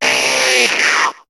Cri de Chrysacier dans Pokémon HOME.